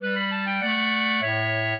clarinet
minuet14-7.wav